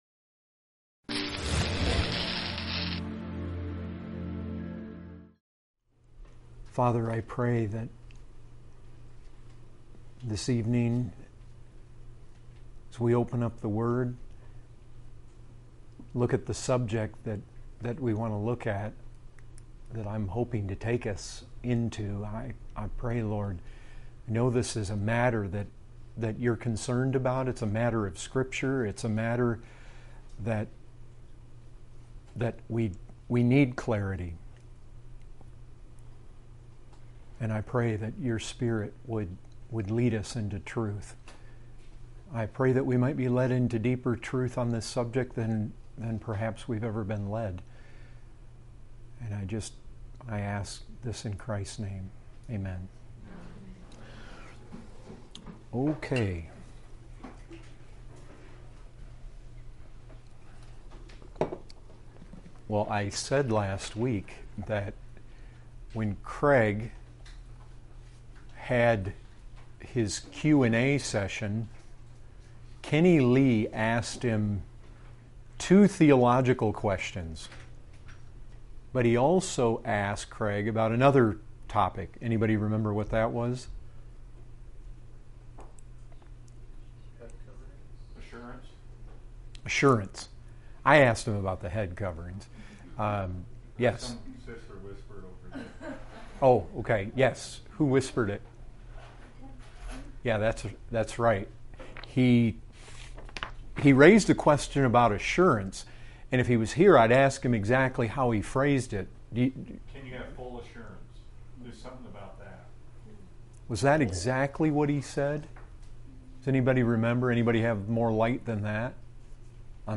2018 Category: Questions & Answers Topic